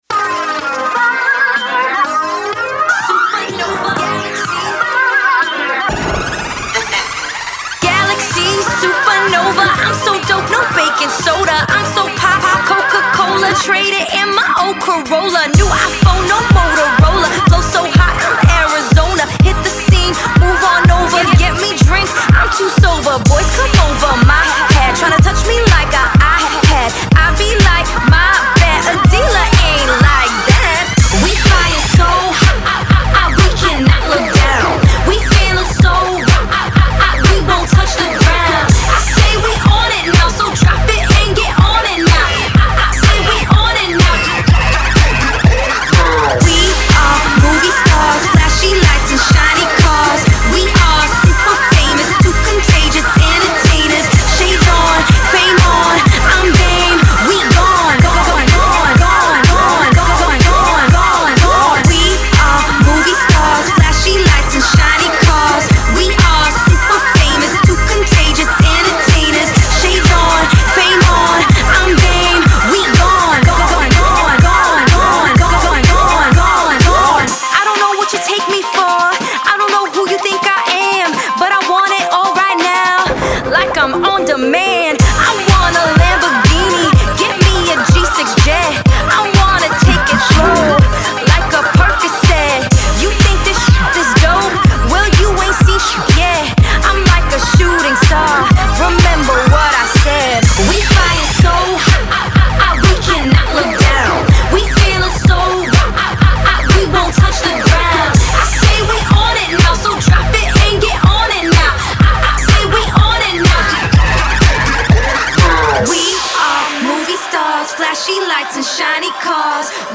It has great crossover appeal,lets dance!!